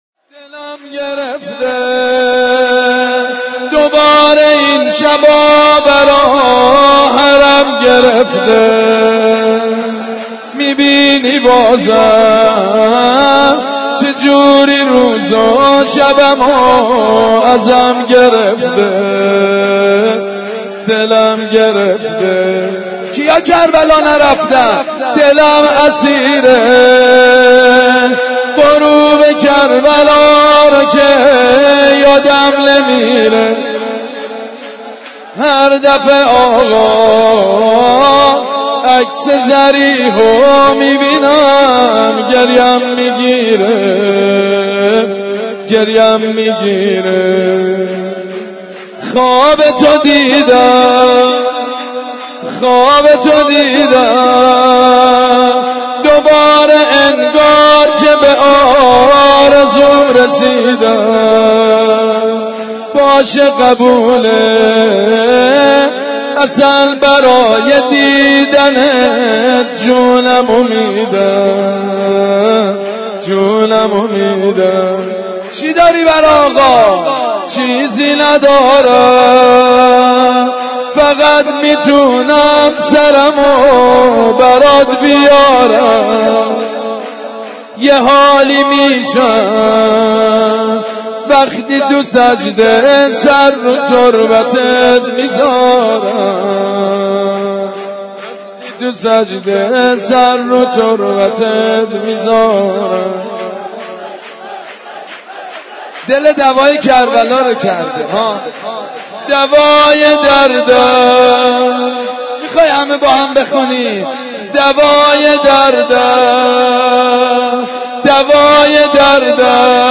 گلچینی از مولودی خوانی مجید بنی فاطمه ویژه حضرت علی اکبر(ع) + صوت
گلچین مولودی خوانی ولادت حضرت علی اکبر(ع) با نوای سیدمجید بنی فاطمه را از صفحه فرهنگی خبرگزاری دانشجو بشنوید.